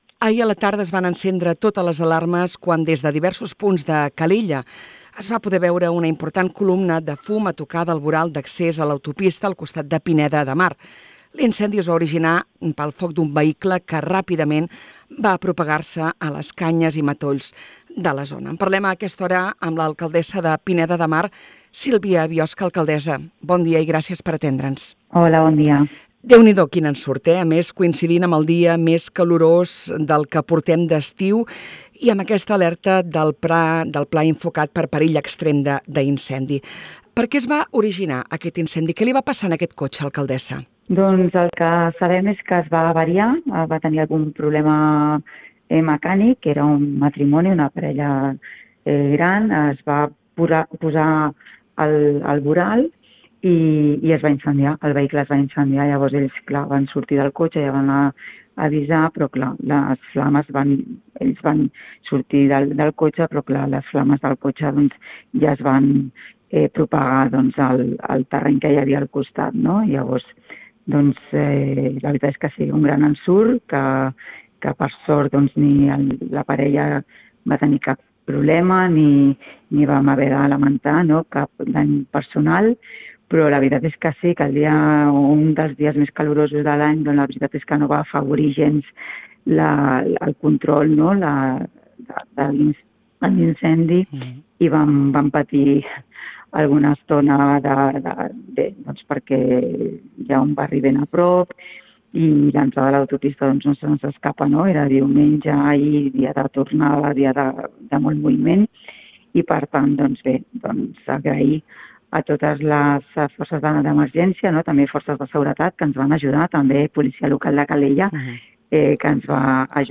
A continuació podeu escoltar l’entrevista sencera a l’alcaldessa de Pineda de Mar, Sílvia Biosca.
ENTREVISTA-SILVIA-BIOSCA-INCENDI.mp3